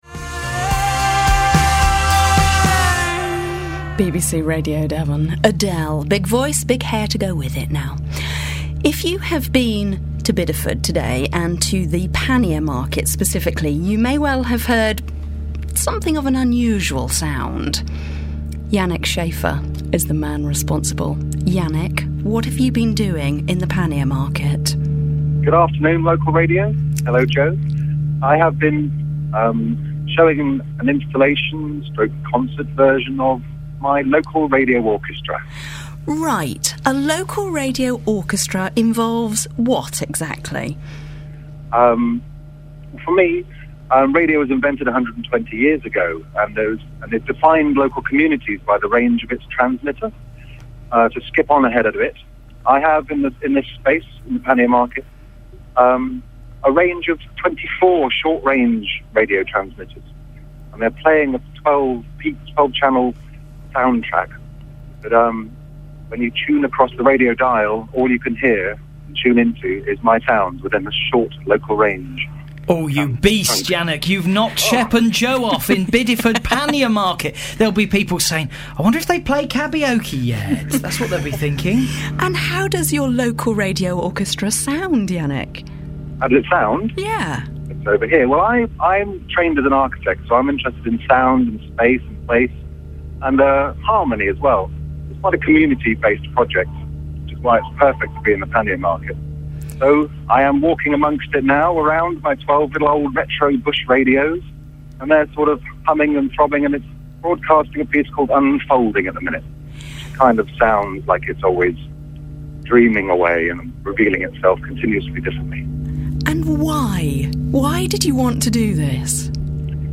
BBC Radio Devon LRO interview.mp3